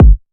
WN - Rich Kick.wav